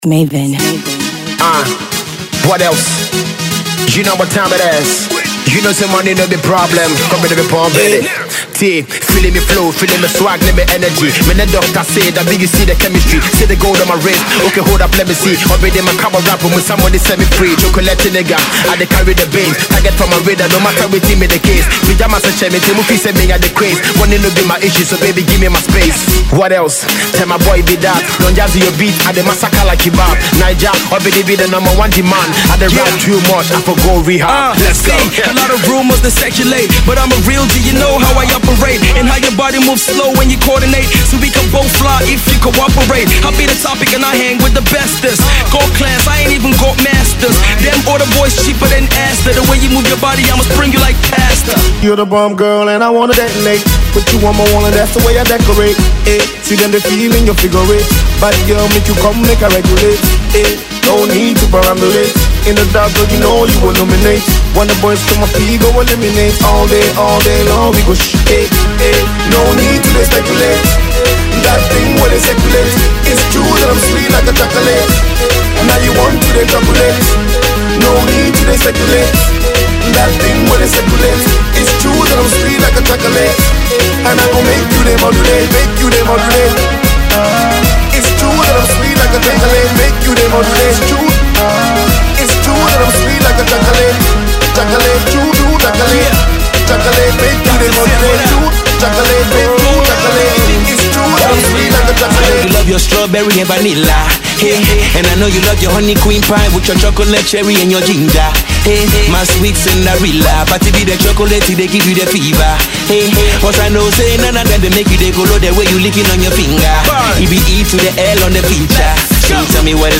so he came back with an even bigger West African remix